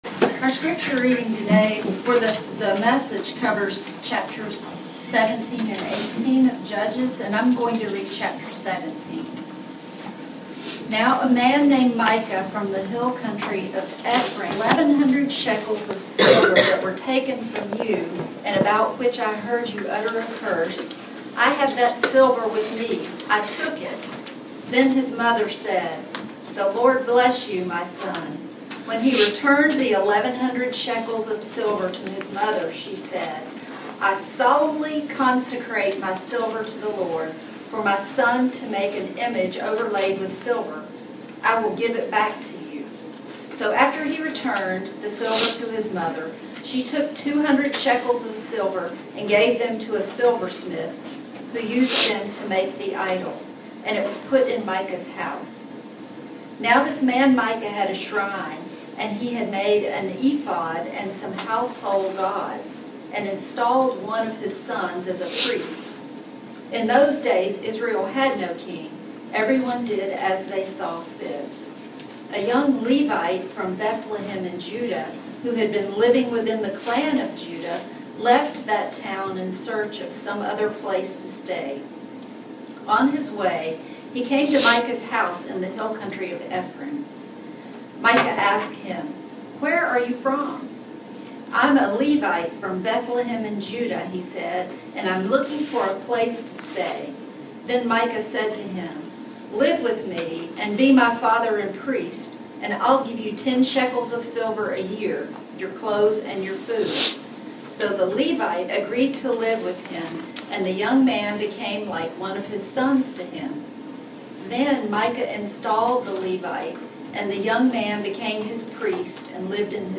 Passage: Judges 17:1-18 Service Type: Sunday Morning